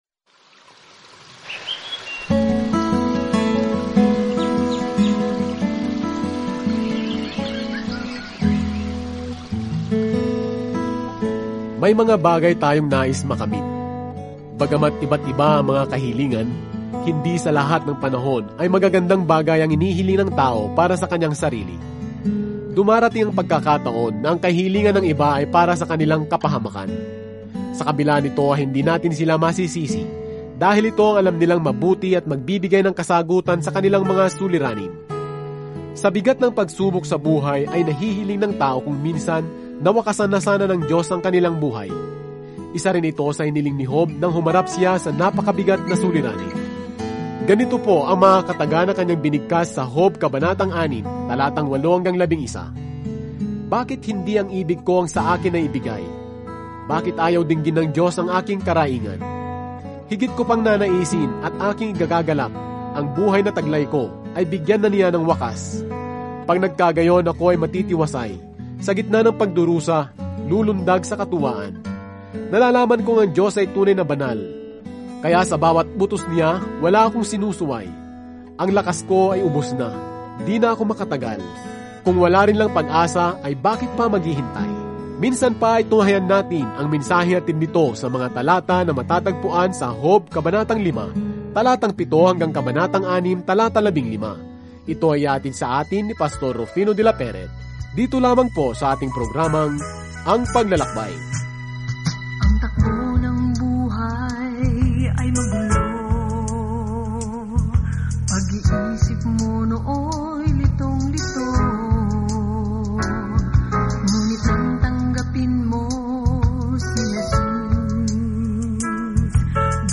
Banal na Kasulatan Job 5:7-27 Job 6:1-15 Araw 5 Umpisahan ang Gabay na Ito Araw 7 Tungkol sa Gabay na ito Sa dramang ito sa langit at lupa, nakilala natin si Job, isang mabuting tao, na pinahintulutan ng Diyos na salakayin ni Satanas; lahat ay may napakaraming katanungan sa paligid kung bakit nangyayari ang mga masasamang bagay. Araw-araw na paglalakbay sa Job habang nakikinig ka sa audio study at nagbabasa ng mga piling talata mula sa salita ng Diyos.